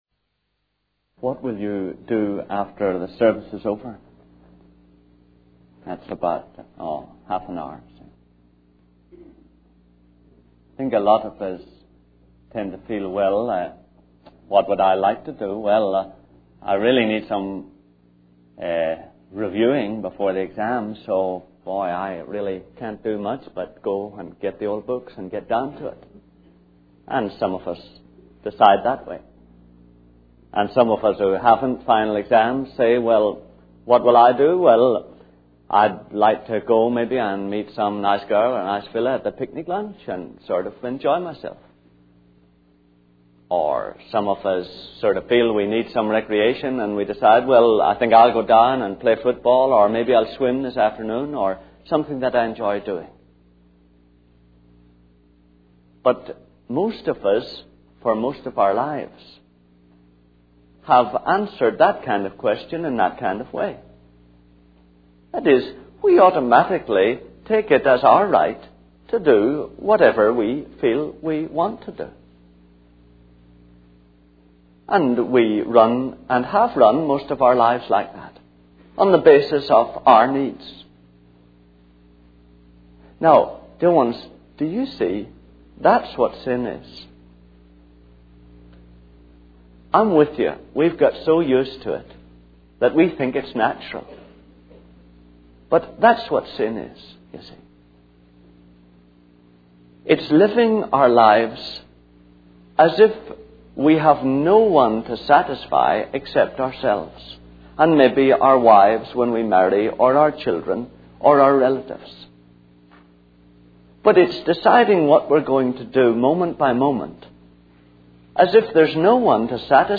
In this sermon, the speaker addresses the tendency of humans to worry and feel strained when unexpected events occur in their lives. The speaker suggests that this strain comes from a lack of belief in an omnipotent being who can weave these unexpected events into a harmonious plan for our lives. The speaker also discusses how sin is rooted in our desire to establish our significance and worth in the universe, as we doubt our place due to being condemned to death for our independence by the creator of the universe.